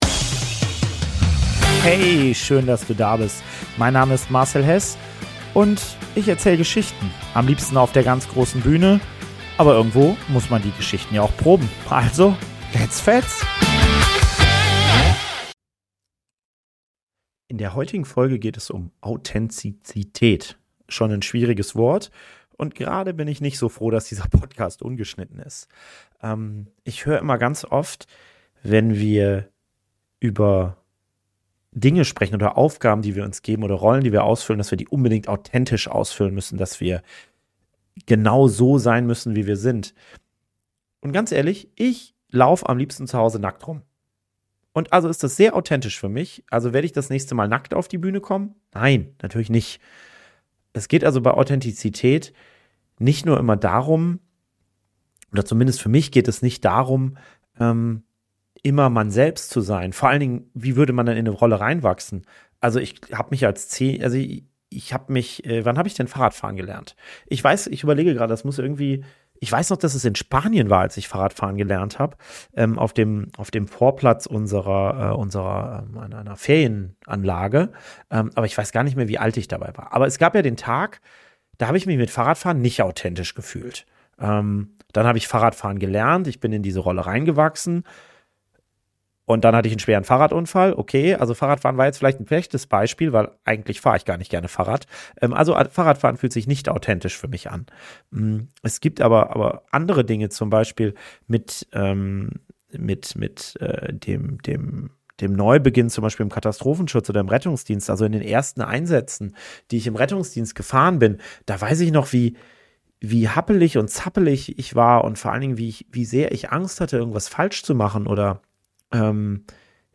Beschreibung vor 1 Jahr In dieser Folge von „GedankenProbe“ tauche ich in das Thema Authentizität ein. Warum habe ich mich entschieden, diesen Podcast ungeschnitten aufzunehmen?